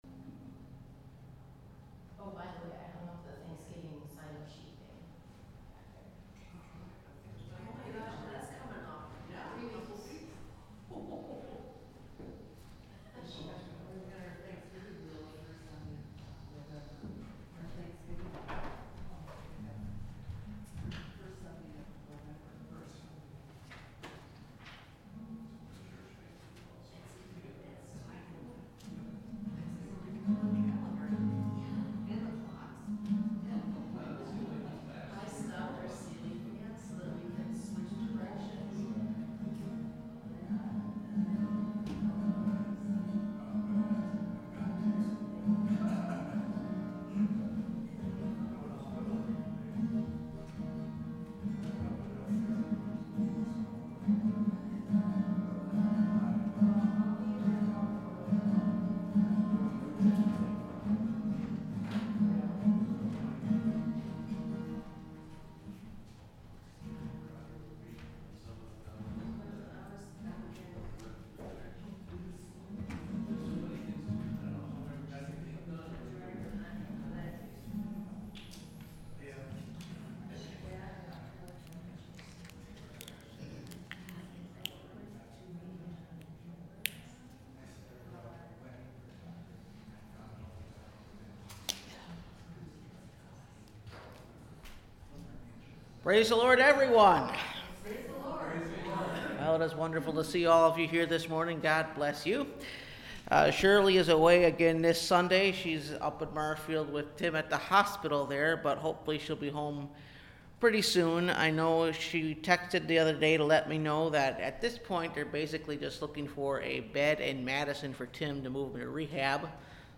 His Way Is Perfect – Last Trumpet Ministries – Truth Tabernacle – Sermon Library